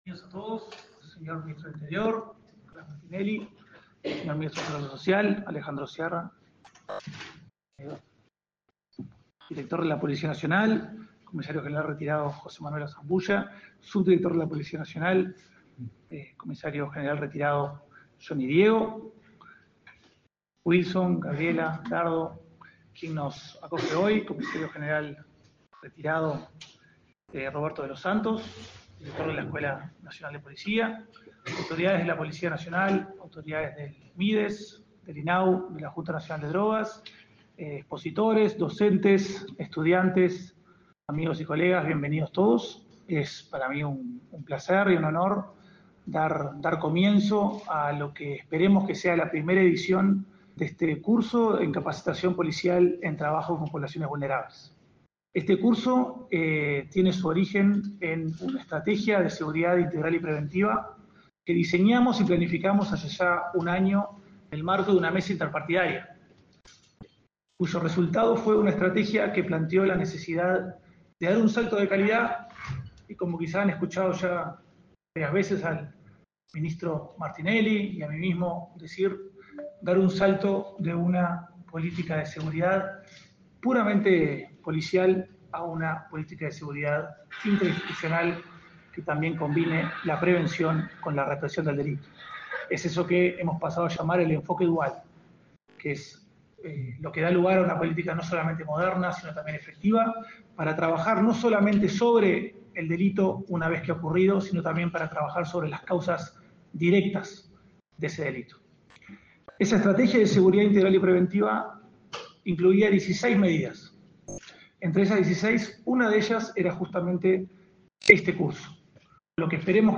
Palabras del coordinador de Estrategias Focalizadas de Prevención Policial del Delito, Diego Sanjurjo
El Ministerio del Interior presentó, este 22 de julio, la capacitación para policías en el trabajo con personas vulnerables, que será impartido por técnicos de la Junta Nacional de Drogas, el INAU y el Mides. El coordinador de Estrategias Focalizadas de Prevención Policial del Delito, Diego Sanjurjo, realizó declaraciones.